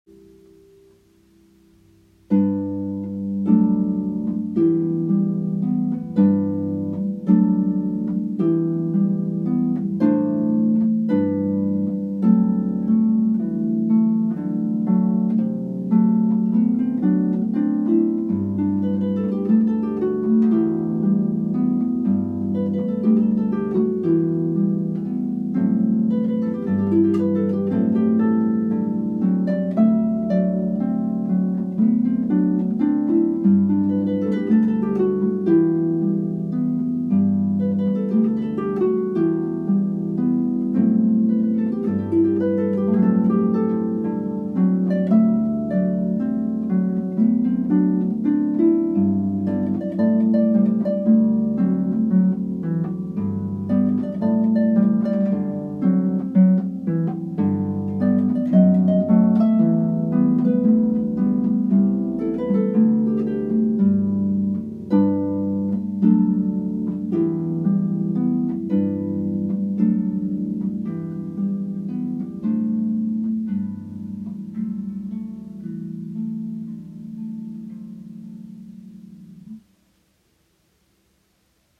Musik zum Reinhören in die Klangwelt der Harfe.